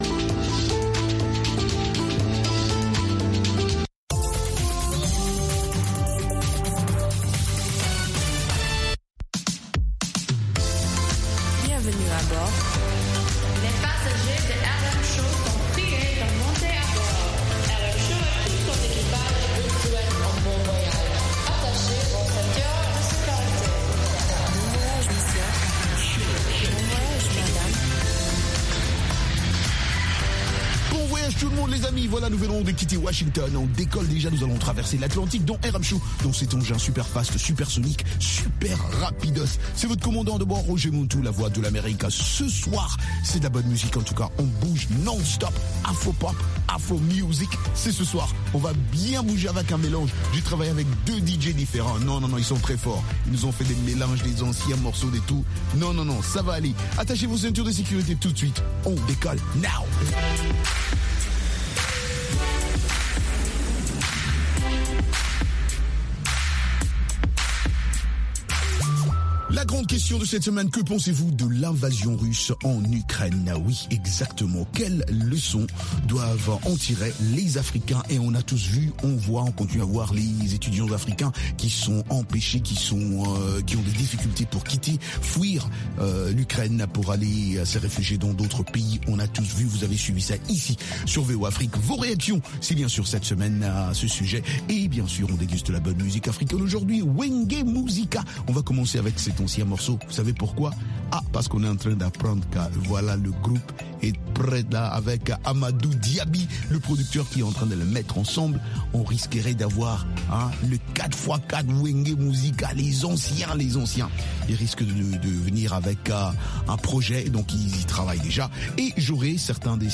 des reportages et interviews sur des événements et spectacles africains aux USA ou en Afrique.